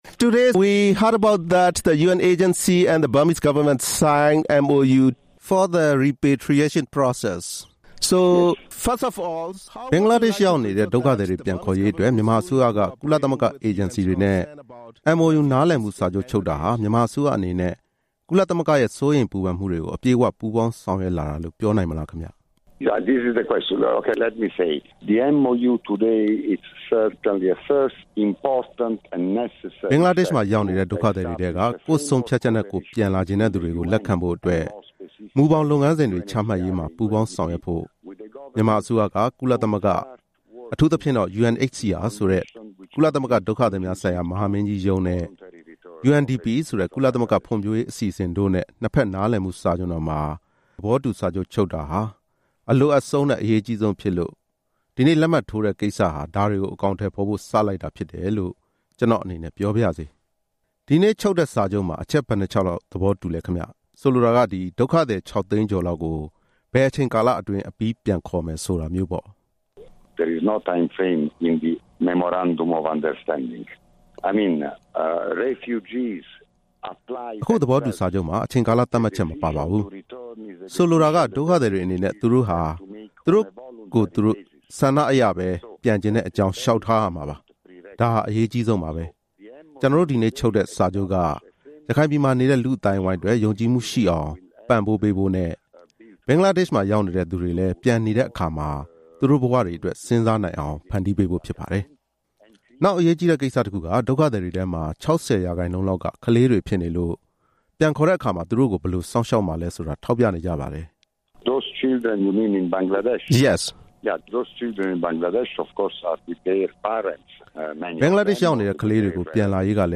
ဒုက္ခသည်ပြန်လာရေးအစီအစဉ်အပေါ် UNHCR နဲ့ဆက်သွယ်မေးမြန်းချက်